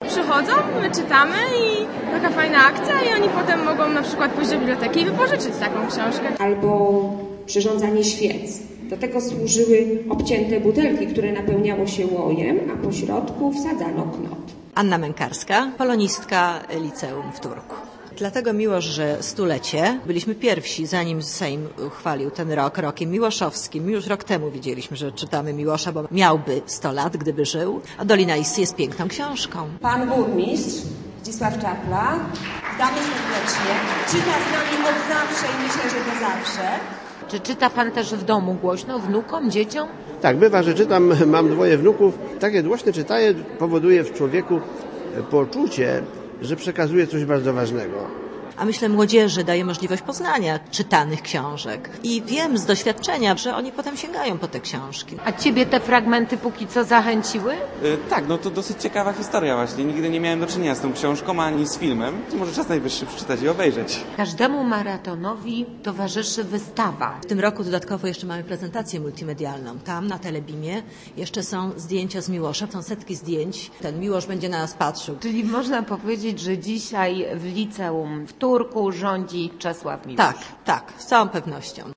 A to za sprawą trwającego tam od rana VIII Maratonu Literackiego. Do głośnego czytania wybrano "Dolinę Issy" noblisty. W rolę lektorów wcielili się uczniowie, nauczyciele, dziennikarze, samorządowcy i szefowie miejskich instytucji.